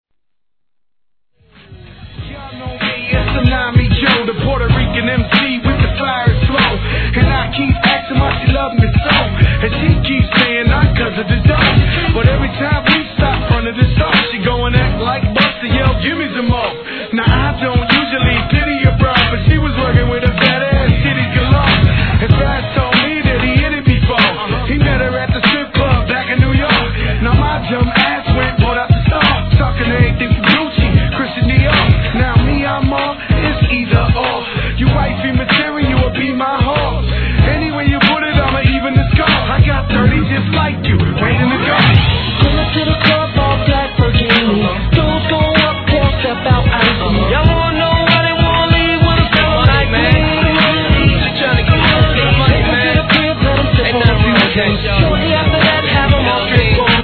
HIP HOP/R&B
BPM 95